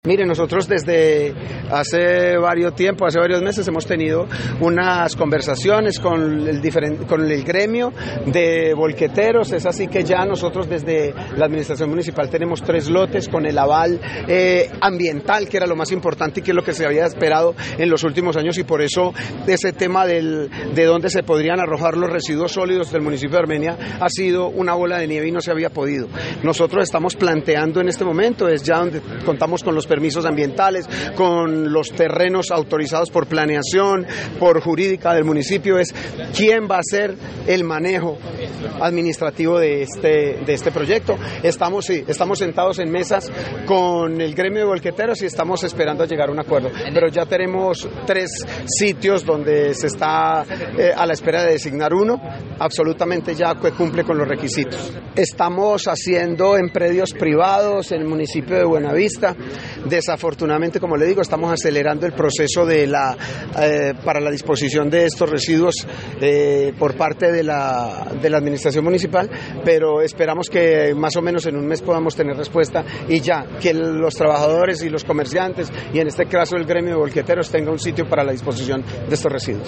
Alcalde de Armenia